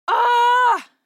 دانلود صدای دعوا 30 از ساعد نیوز با لینک مستقیم و کیفیت بالا
جلوه های صوتی